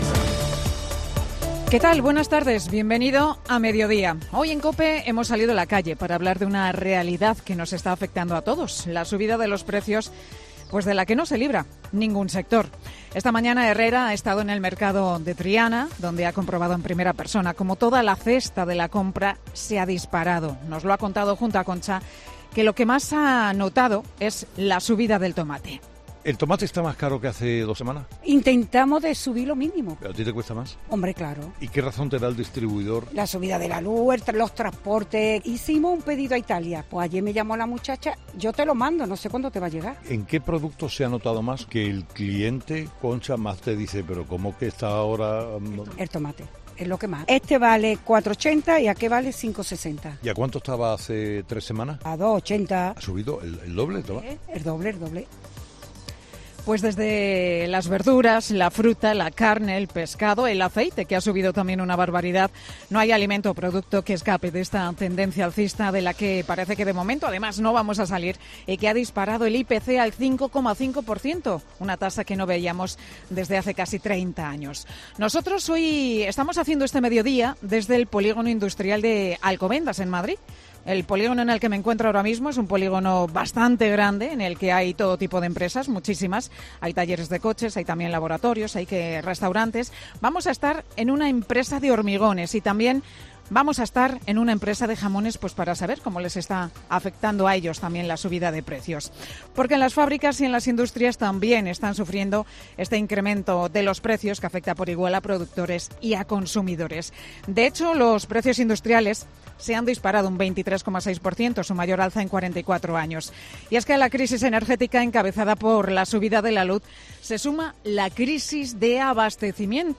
Monólogo de Pilar García Muñiz